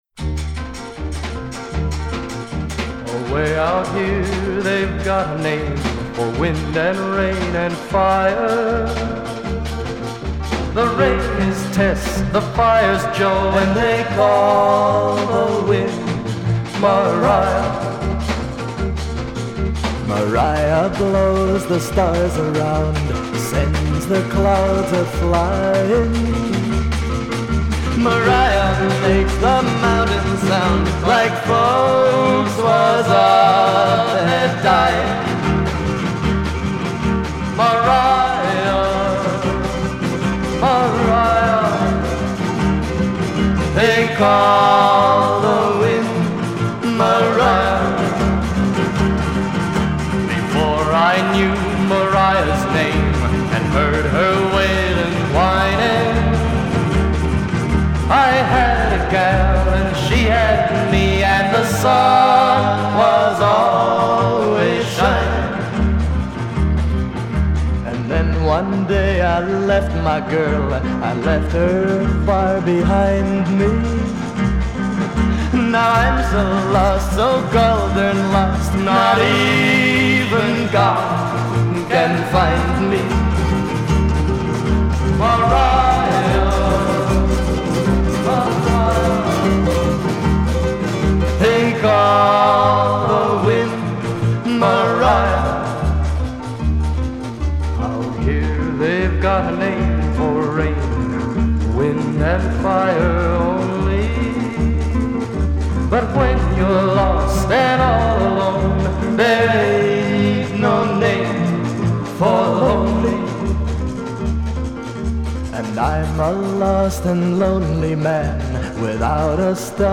show tunes